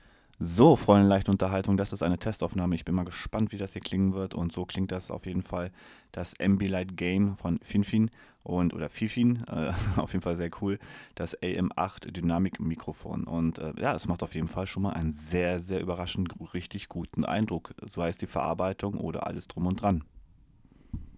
Im USB-Modus ist das Mikrofon komplett unkompliziert:
Test-Aufnhame.wav